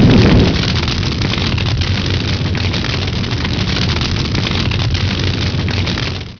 flamethrower.wav